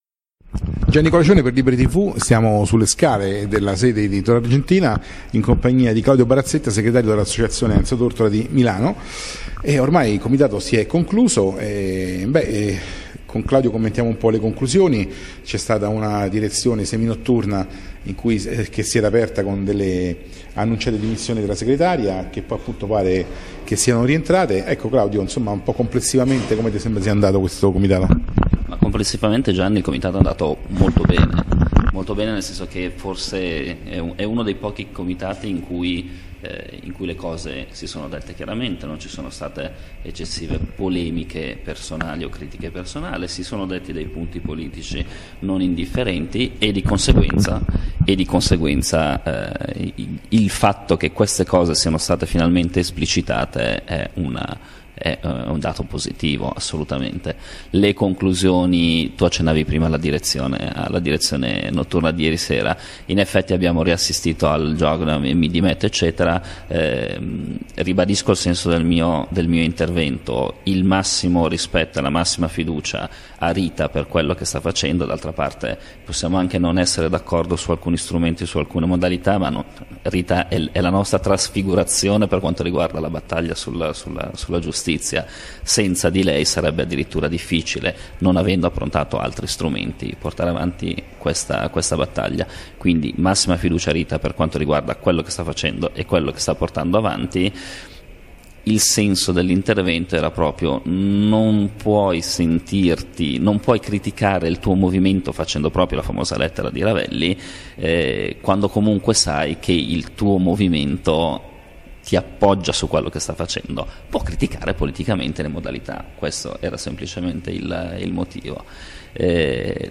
Comitato Nazionale Radicali Italiani 11-12-13 aprile 2014, Roma presso la sede del Partito Radicale.
Intervista